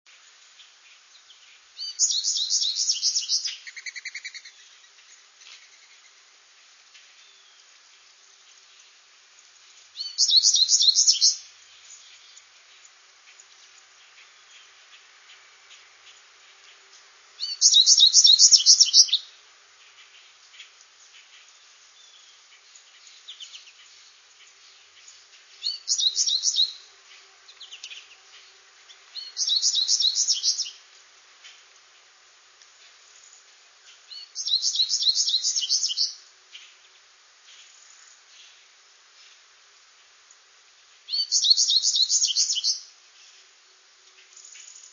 Goldfinch
Wellfleet, Cape Cod, MA Maurice's Campground, 8/8/02, (175kb), melodious perching song with Mourning Dove flying off and Black-capped Chickadees call and feeding buzz.  Pitch pine forest.  Notice introductory "wee" and three-part song with variations in endings on each part.
goldfinchsong682.wav